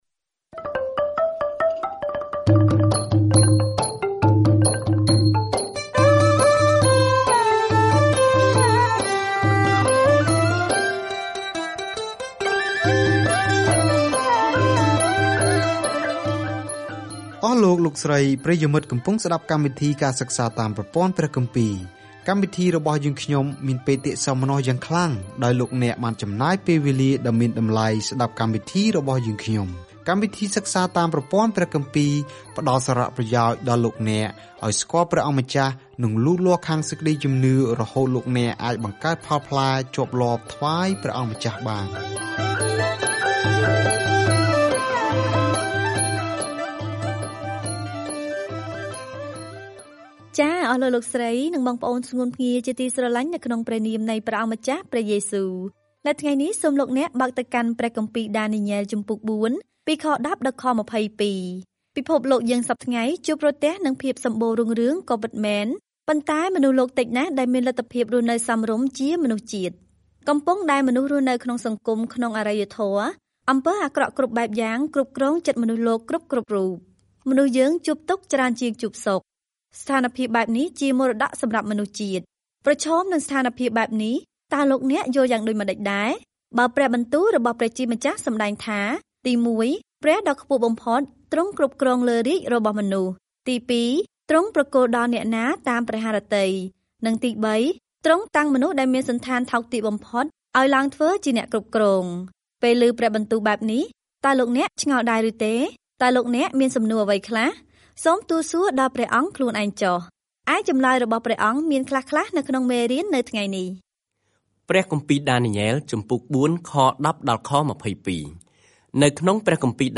សៀវភៅដានីយ៉ែលគឺជាសៀវភៅជីវប្រវត្តិរបស់បុរសម្នាក់ដែលបានជឿព្រះ និងជាទស្សនវិស័យព្យាករណ៍អំពីអ្នកដែលនឹងគ្រប់គ្រងពិភពលោកនៅទីបំផុត។ ការធ្វើដំណើរជារៀងរាល់ថ្ងៃតាមរយៈដានីយ៉ែល នៅពេលអ្នកស្តាប់ការសិក្សាជាសំឡេង ហើយអានខគម្ពីរដែលជ្រើសរើសពីព្រះបន្ទូលរបស់ព្រះ។